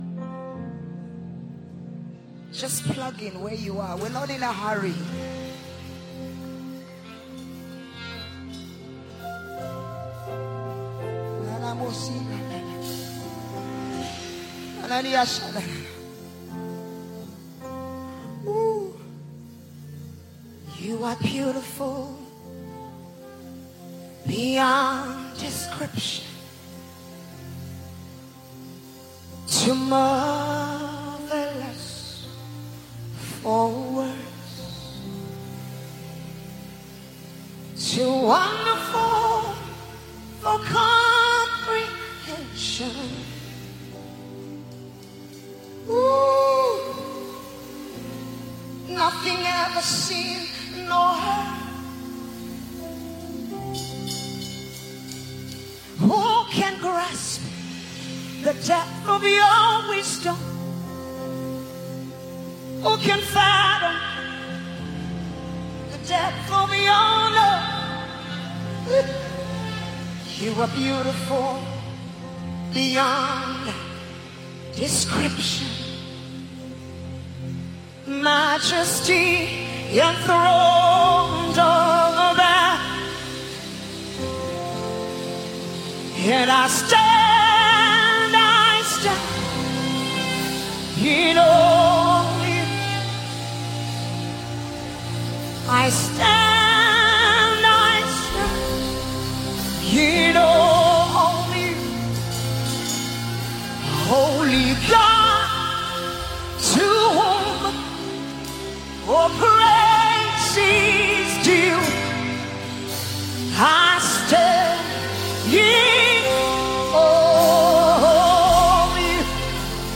tender yet authoritative vocals